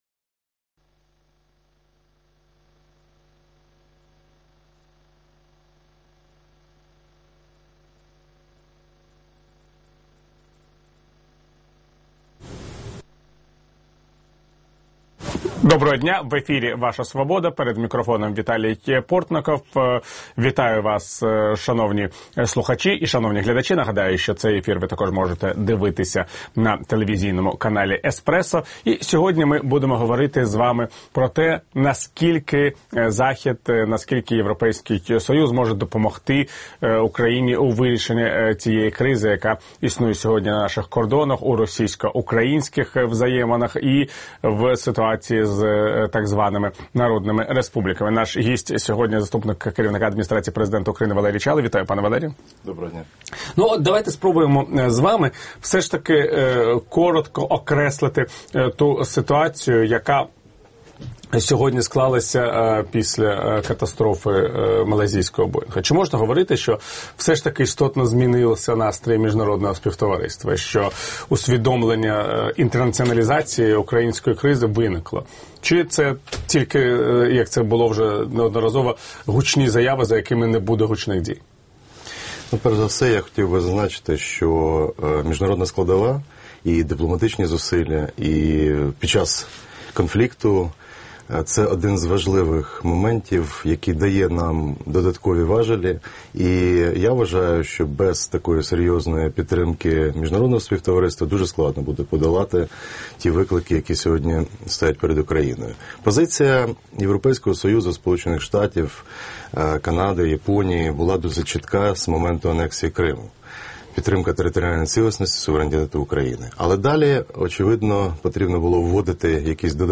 Гість «Вашої свободи»: Валерій Чалий, заступник голови адміністрації президента України.